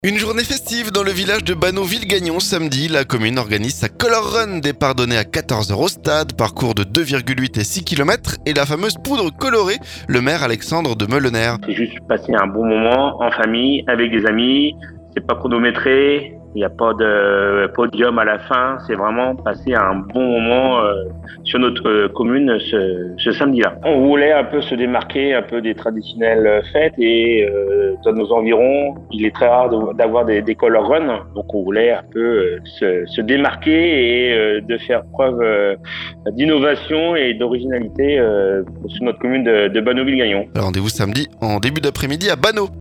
Le maire, Alexandre de Meulenaere.